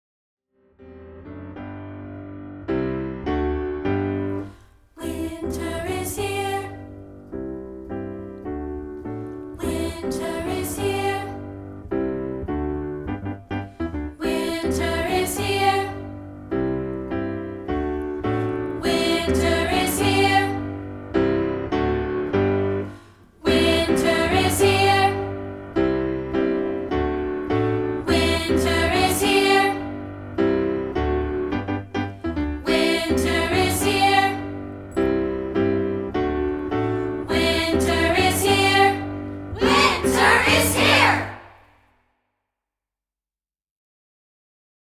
we've isolated the lower divisi part for rehearsal purposes.